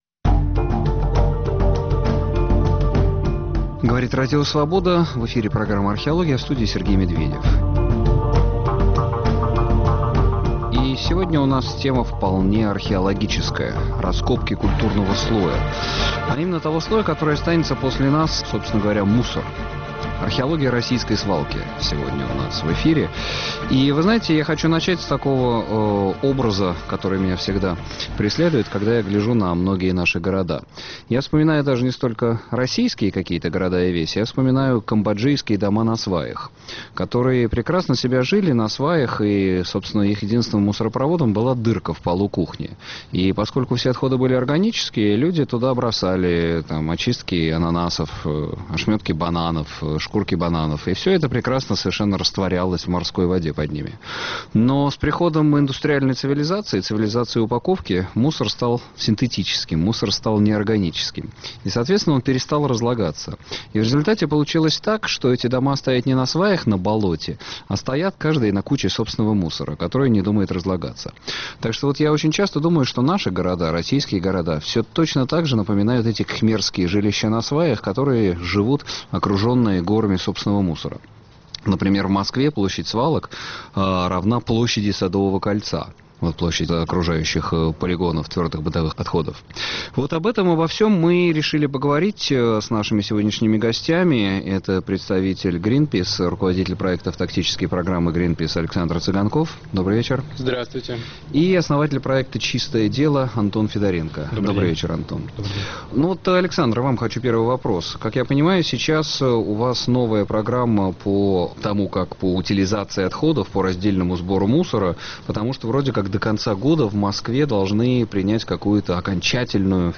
Российские города в кольце свалок Гости в студии